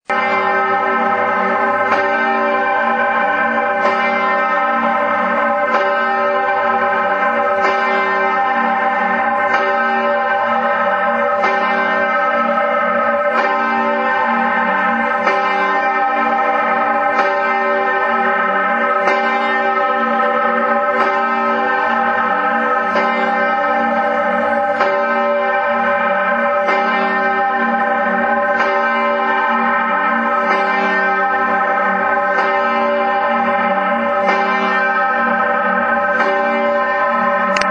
Herz-Jesu-Glocke Lana